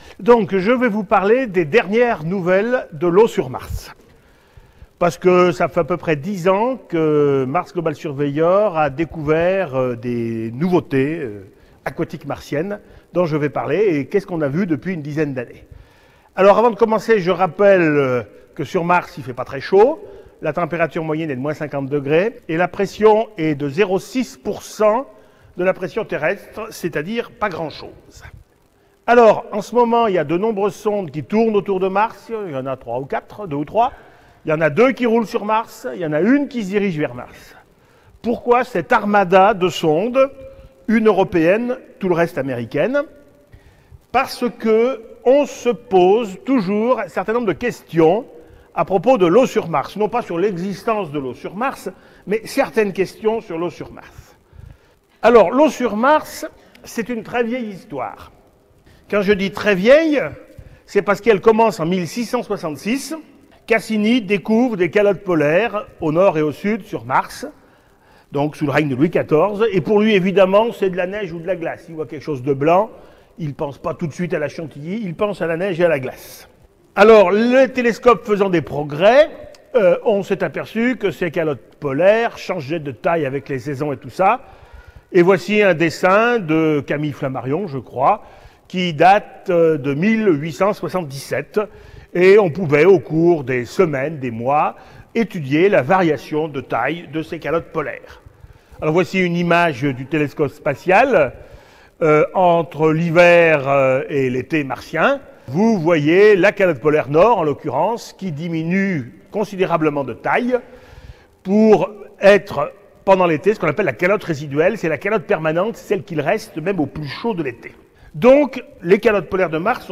Écouter la conférence L'eau sur Mars, bilan fin 2007.